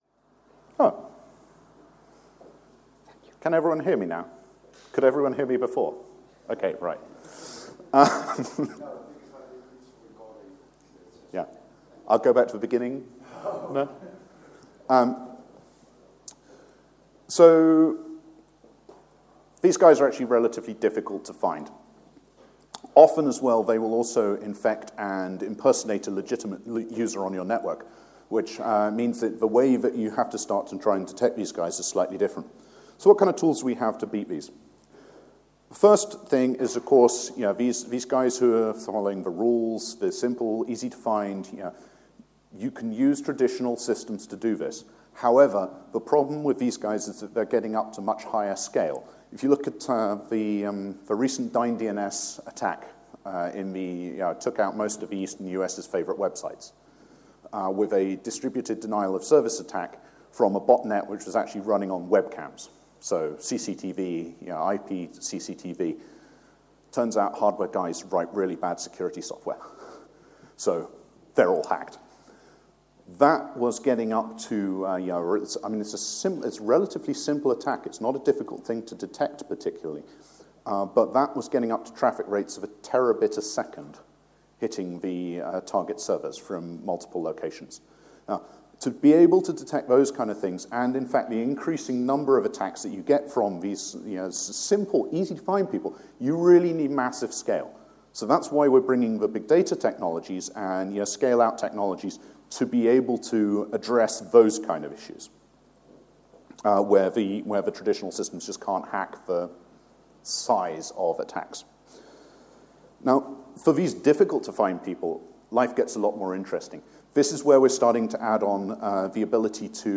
More information about this talk Categories: Uncategorized • Tags: ApacheCon , metron , Podcasts , seville • Permalink